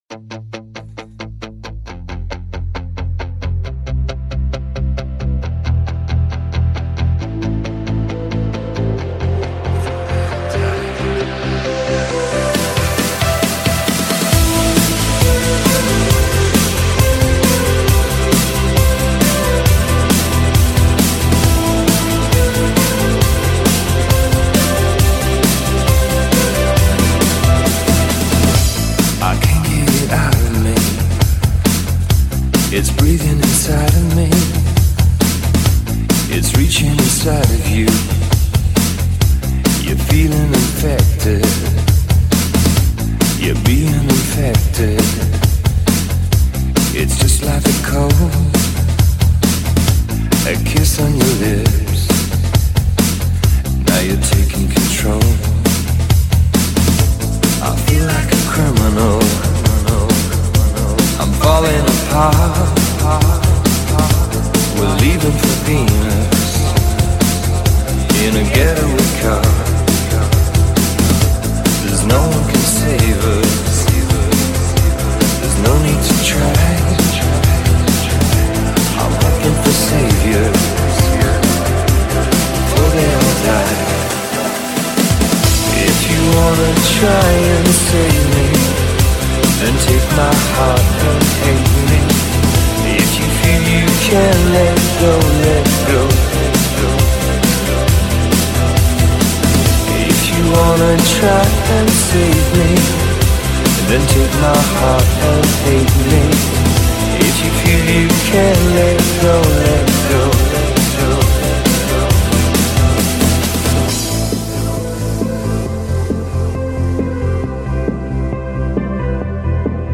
Club | [